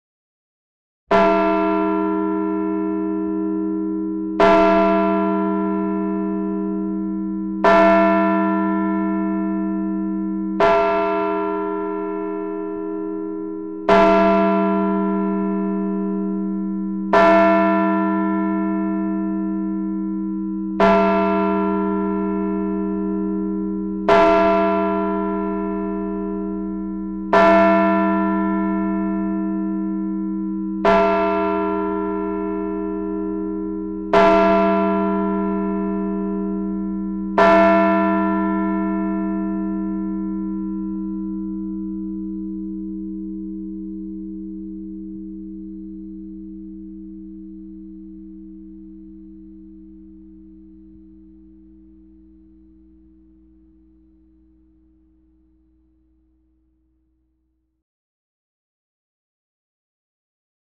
Throughout the play, church bells indicate the passage of time and the evolution of the show. I adjusted the bells to match the feel of the show at each point.
First Bells
19-Bells-12-OClock.mp3